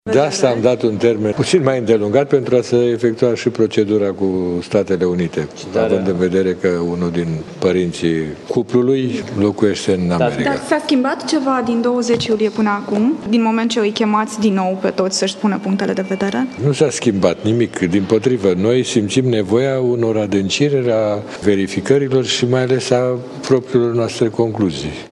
Preşedintele Curţii, Valer Dorneanu: